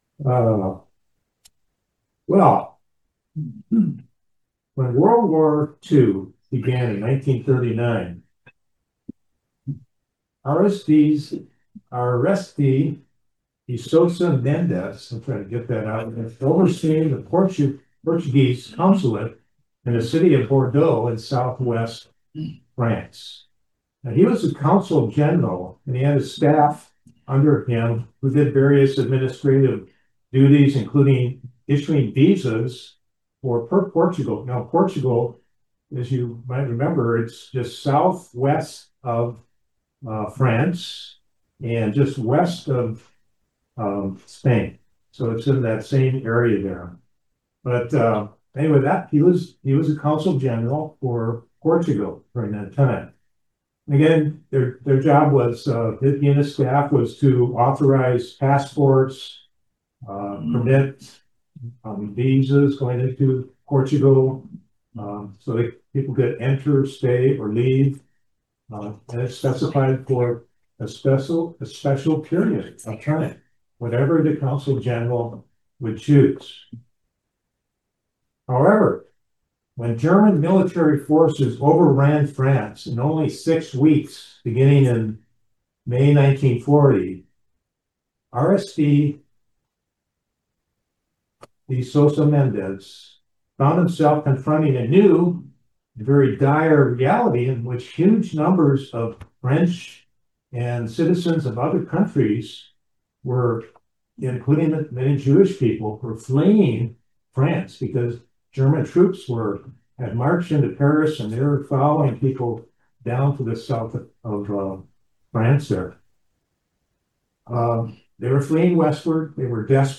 We have to give our Love to please God. Join us for this sermon about the greatest love you can give.